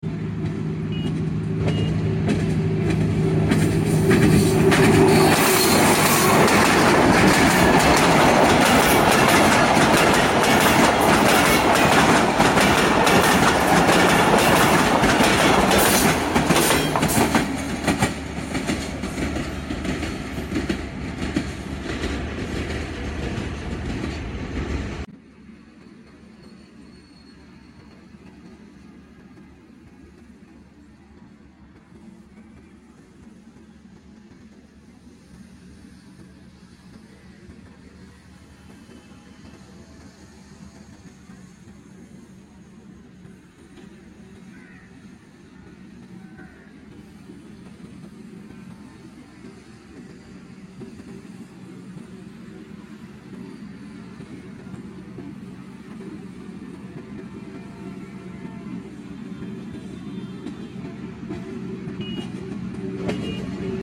Mitti express crossing Vehari //